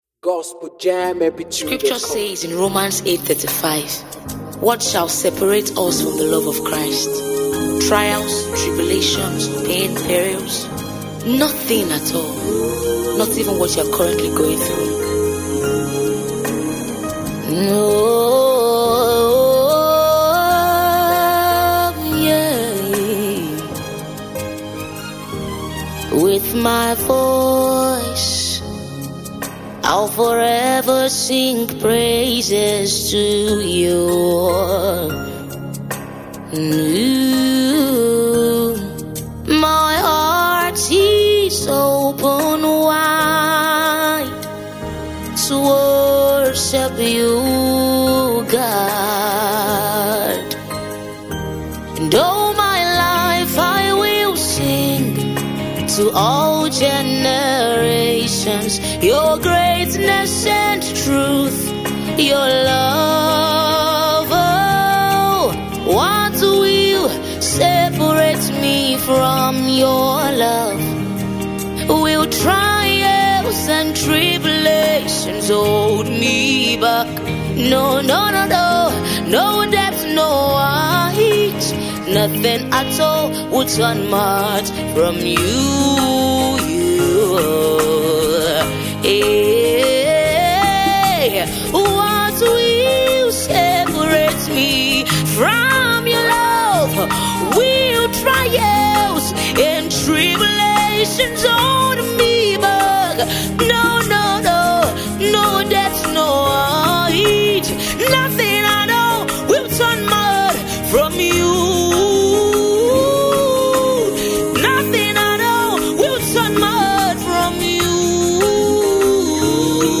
a powerful gospel song of gratitude and bold declaration
With heartfelt lyrics and uplifting melodies